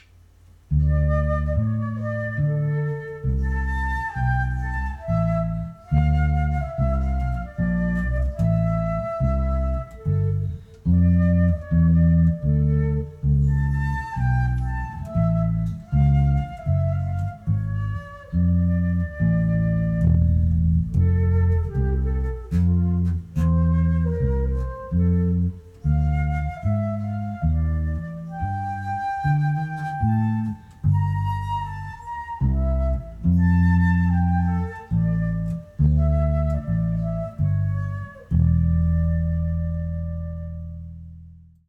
Bass-Spiel Stereo LIVE
Sondern hier gibt jeder Tonabnehmer aufgrund seiner Position auf dem Korpus und der Länge der Saiten einen drastisch anderen Klang ab.
Mein Bass-Spiel wurde live aufgezeichnet , parallel zum PlayAround-Flötenspiel vom Band .
Der Klang im Raum wurde über ein Raum-Mikrophon, über eine Behringer U-PHORIA UMC22 DAW
Der Bass spielt Stereo
Flute & Bass - Übung.mp3